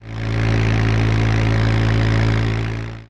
CosmicRageSounds / ogg / general / highway / oldcar / tovertake5.ogg